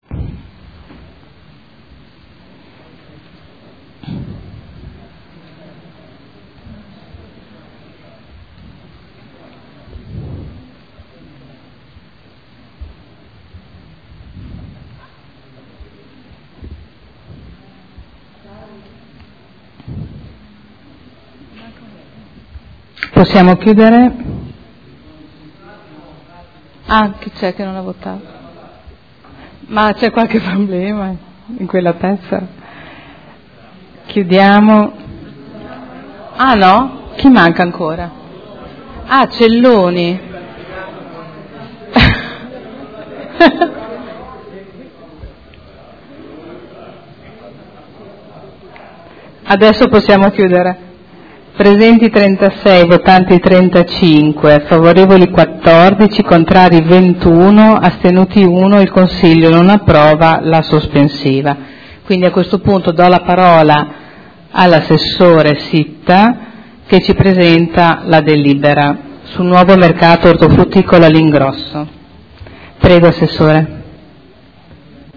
Presidente — Sito Audio Consiglio Comunale
Seduta del 18/06/2012. Mette ai voti sospensiva alla proposta di deliberazione chiesta dal Consigliere Morandi. Nuovo Mercato Ortofrutticolo all'Ingrosso